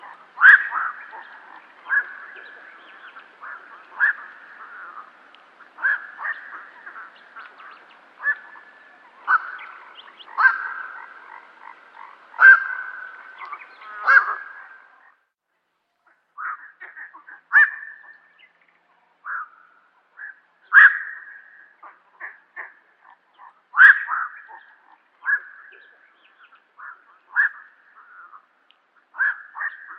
Héron bihoreau - Mes zoazos
heron-bihoreau-1.mp3